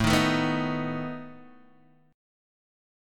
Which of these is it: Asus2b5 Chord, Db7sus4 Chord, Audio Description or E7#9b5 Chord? Asus2b5 Chord